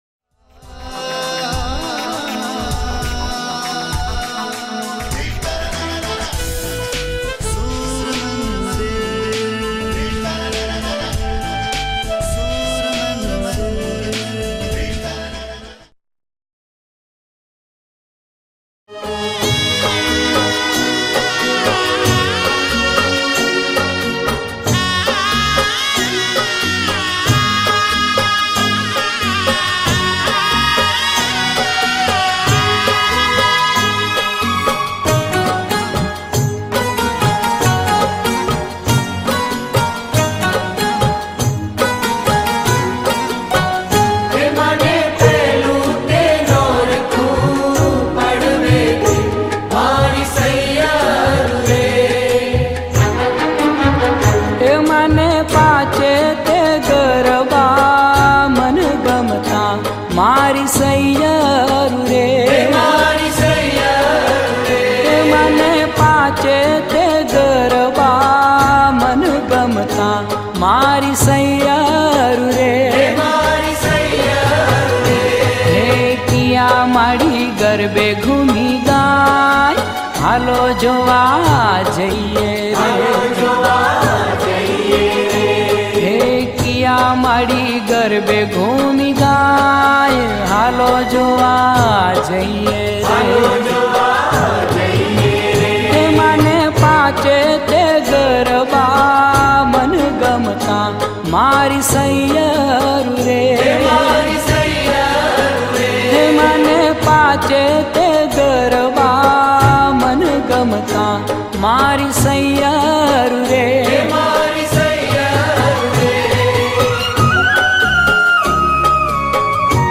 ગીત સંગીત ગરબા - Garba
Garba Song.